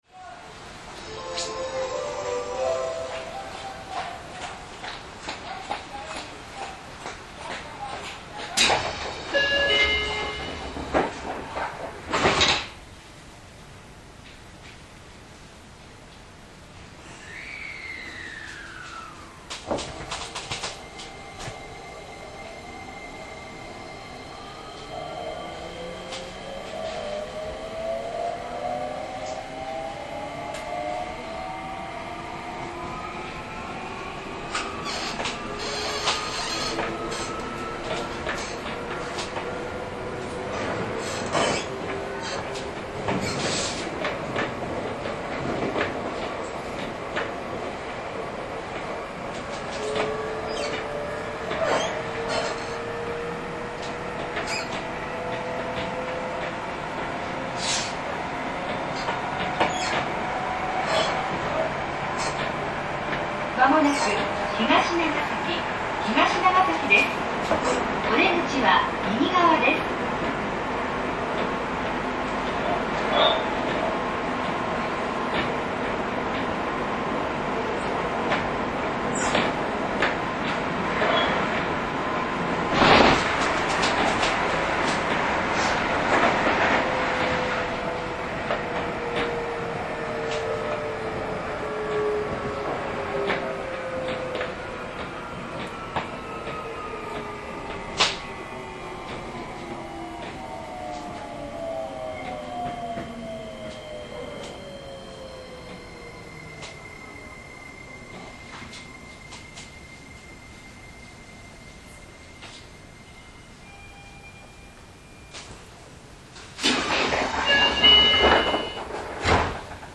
インバーター制御ですが、モーター音が静が過ぎです。
走行音